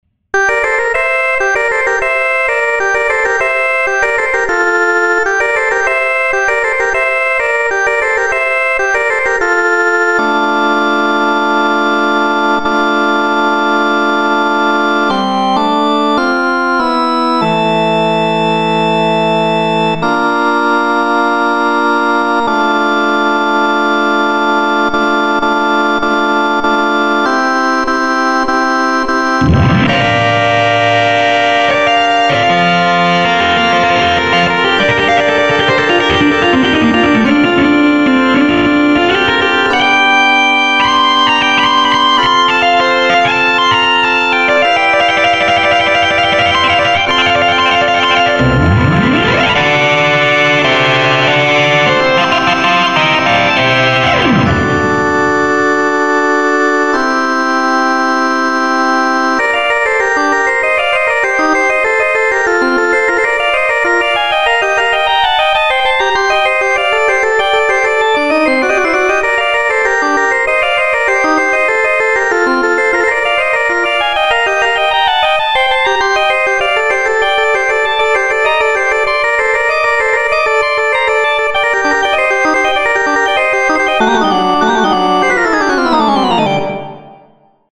●60sTransistor●DirtyRock